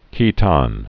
(kētän)